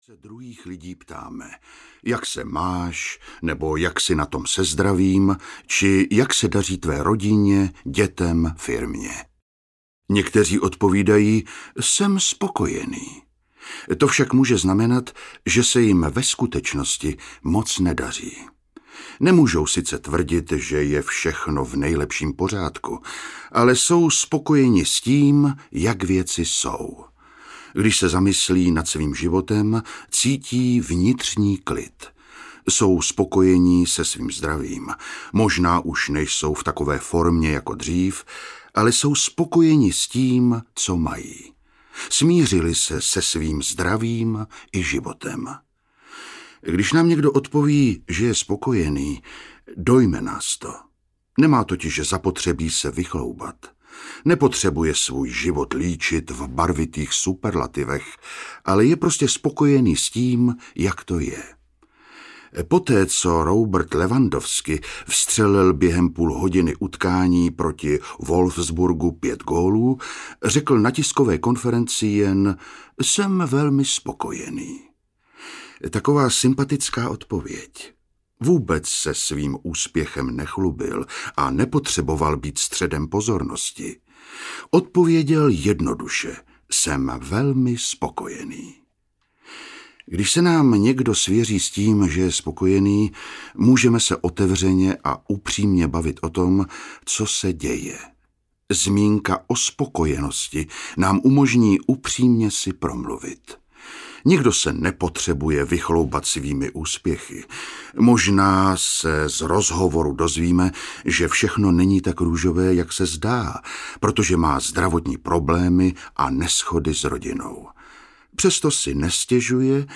Kouzlo maličkostí audiokniha
Ukázka z knihy
kouzlo-malickosti-audiokniha